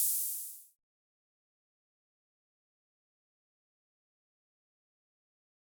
Open Hat (7).wav